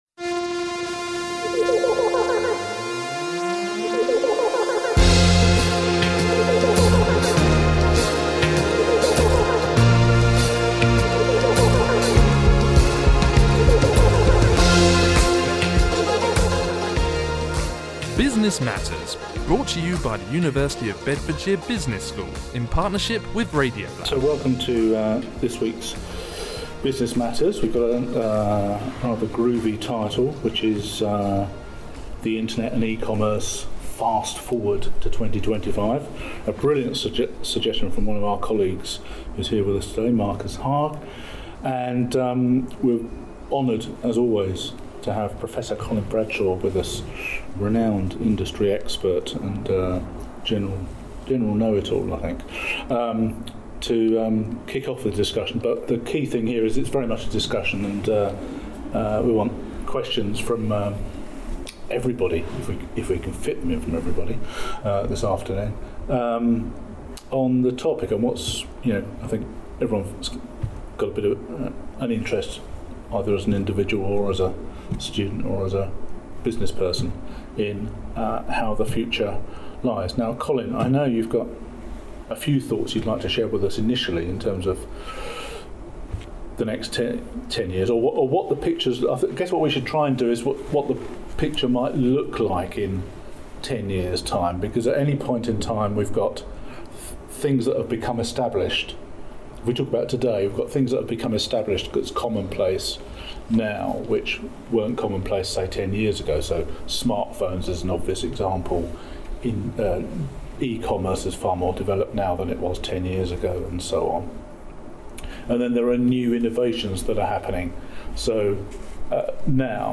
for what is bound to be a lively and thought-provoking discussion.